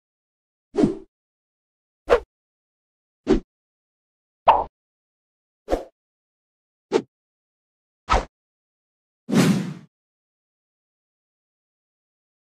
Sword Swoosh Sound Effect Free Download
Sword Swoosh